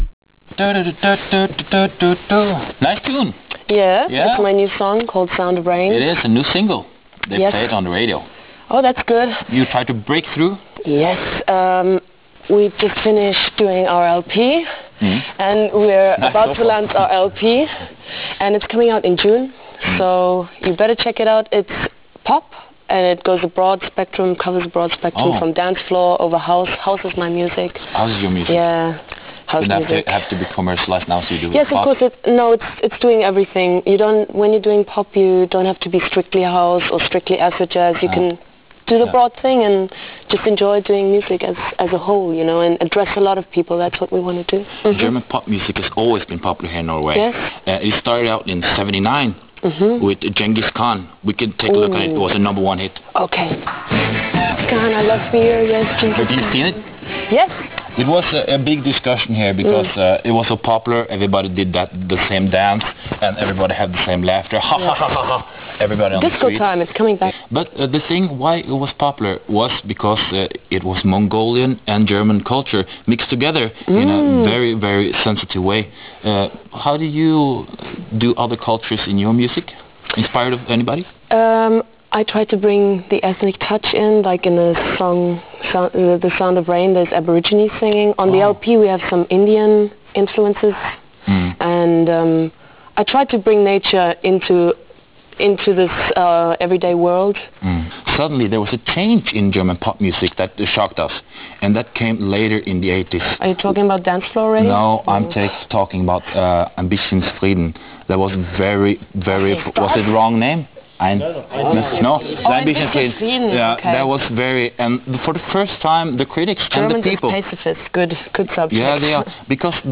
Lille Lørdag fjernsynsradio: Intervju